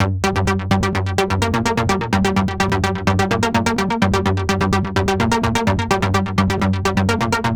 Monosyn G 127.wav